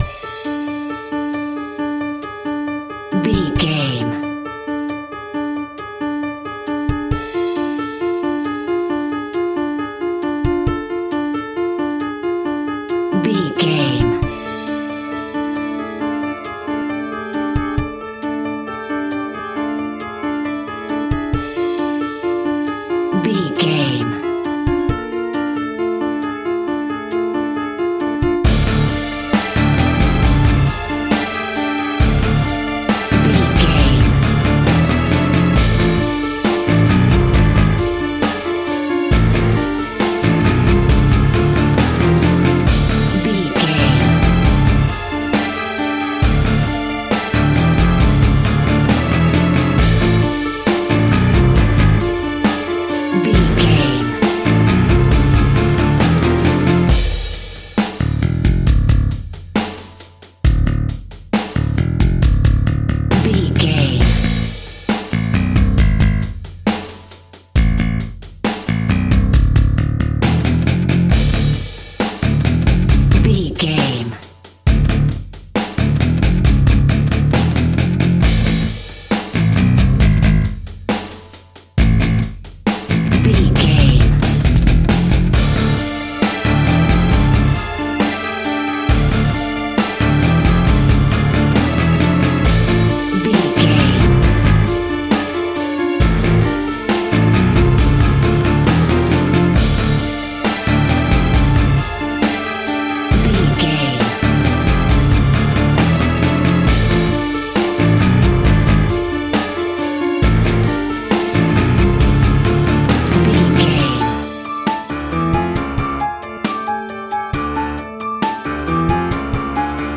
In-crescendo
Aeolian/Minor
scary
ominous
suspense
haunting
eerie
piano
drums
organ
electric guitar
bass guitar
strings
synth
pads